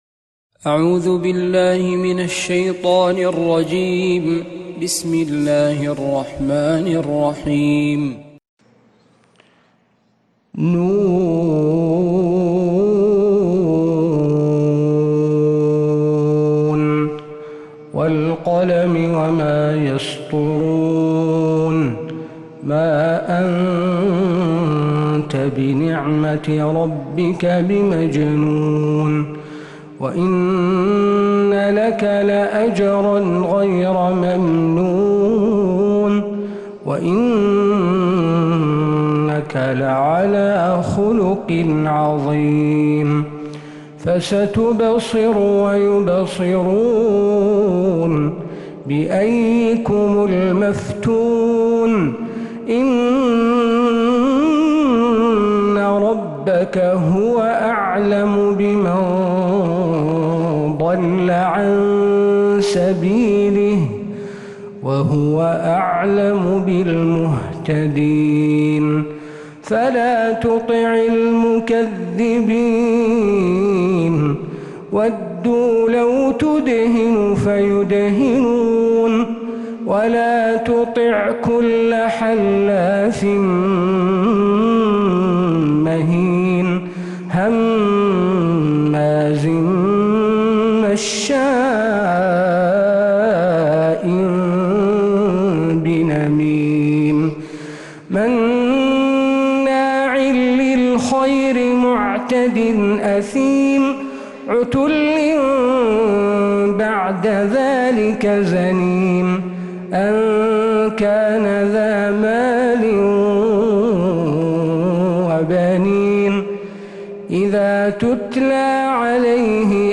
سورة القلم كاملة من فجريات الحرم النبوي